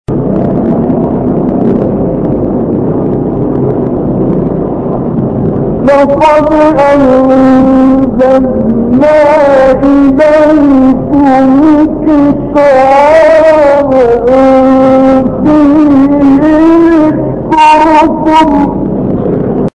9 فراز از «کامل یوسف» در مقام صبا
گروه شبکه اجتماعی: فرازهای صوتی از کامل یوسف البهتیمی که در مقام صبا اجرا شده است، ارائه می‌شود.
به گزارش خبرگزاری بین‌‌المللی قرآن(ایکنا) 9 مقطع صوتی از کامل یوسف البهتیمی، قاری برجسته مصری که در مقام صبا اجرا شده، در کانال تلگرامی کامل یوسف البهتیمی منتشر شده است، در زیر ارائه می‌شود.
برچسب ها: خبرگزاری قرآن ، ایکنا ، شبکه اجتماعی ، کامل یوسف البهتیمی ، قاری مصری ، مقام صبا ، فراز صبا ، تلاوت قرآن ، مقطعی از تلاوت ، قرآن ، iqna